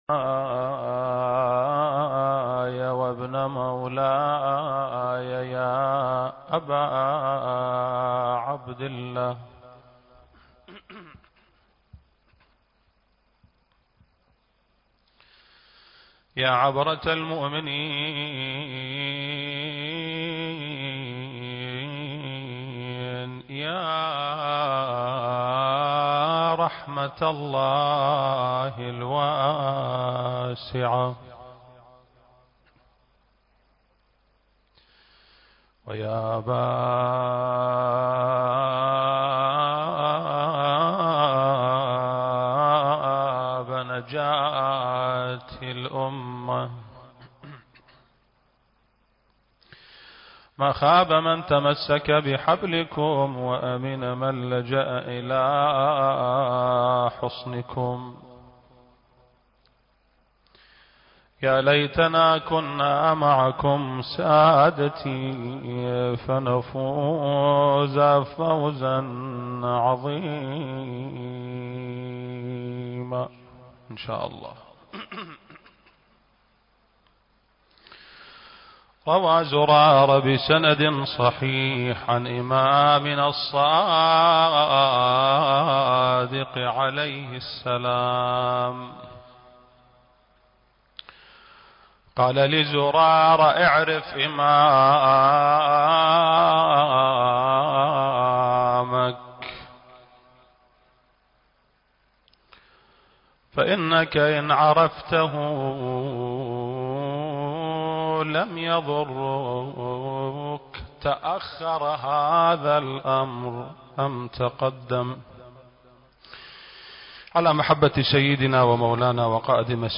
المكان: مسجد آل محمد (صلّى الله عليه وآله وسلم) - البصرة التاريخ: 1442